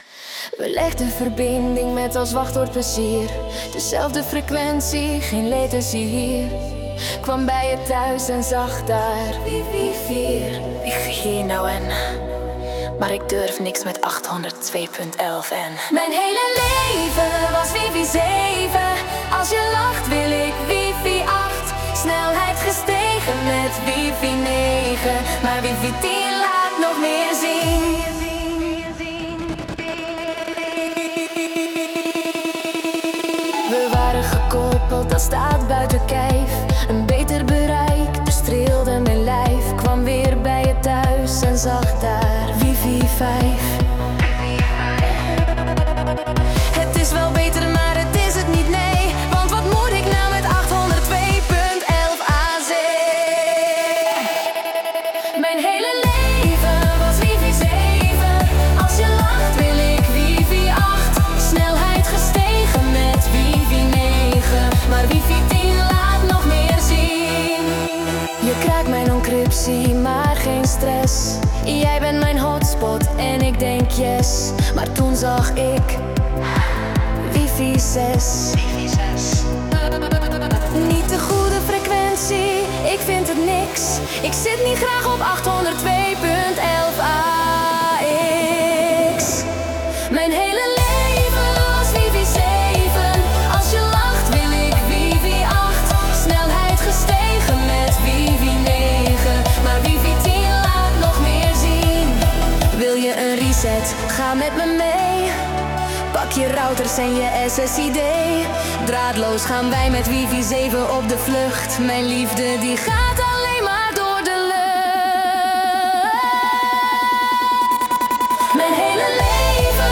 Mijn hele leven was melodic hardstyle (2).mp3